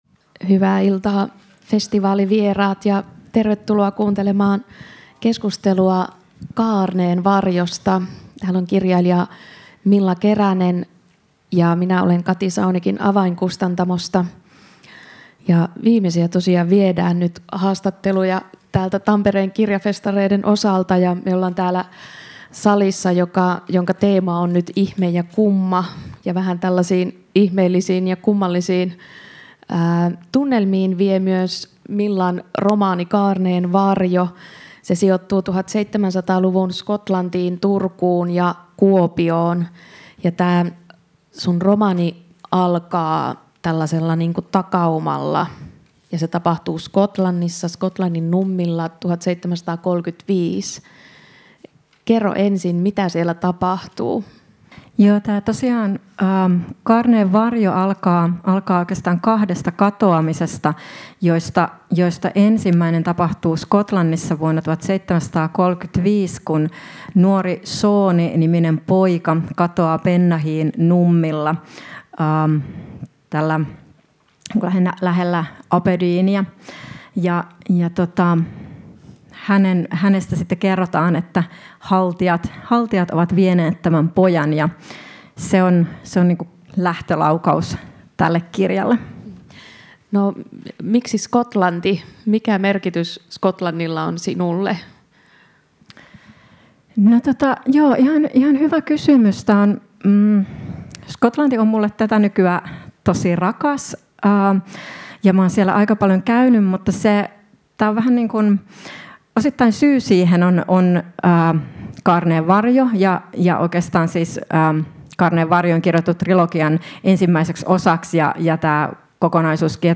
Ohjelma on taltioitu Tampereen Kirjafestareilla 2024. https